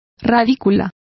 Complete with pronunciation of the translation of radicle.